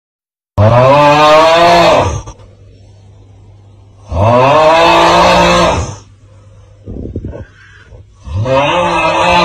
Snoring meme original 🗣 sound effects free download